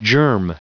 Prononciation du mot germ en anglais (fichier audio)
Prononciation du mot : germ